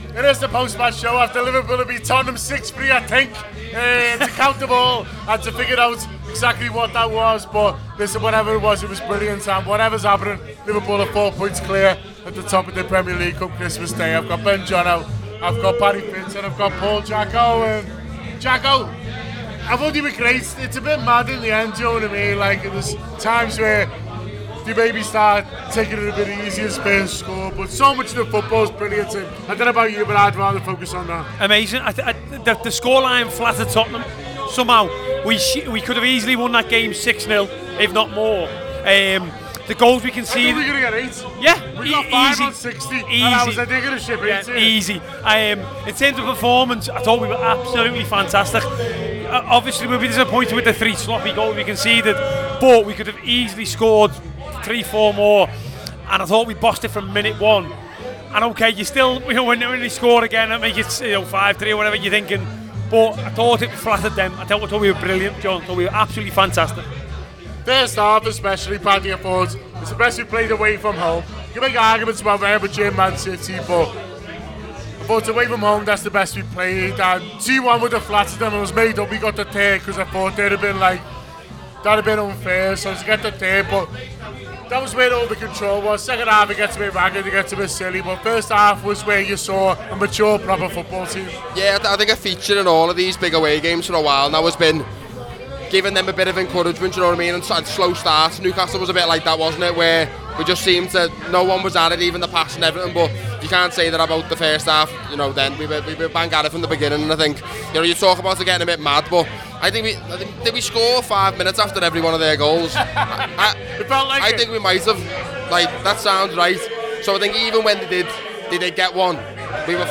The Anfield Wrap’s post-match reaction podcast after Tottenham 3 Liverpool 6 at the Tottenham Stadium in London.